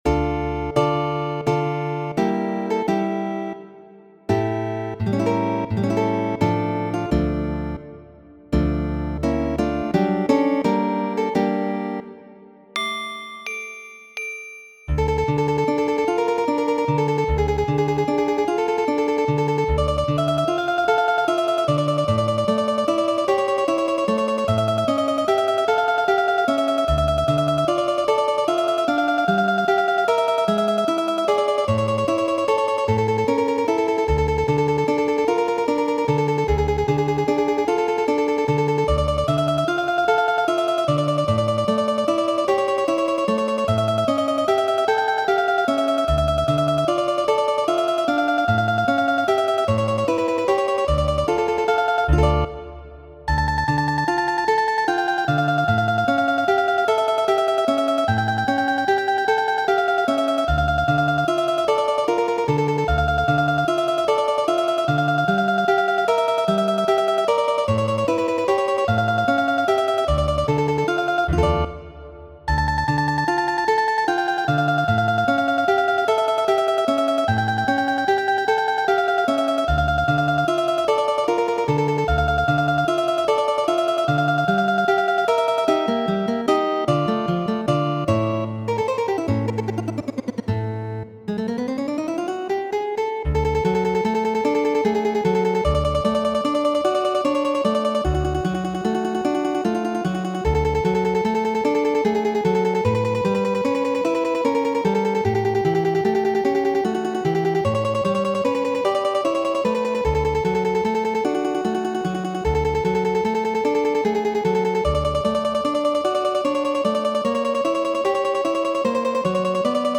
Muziko: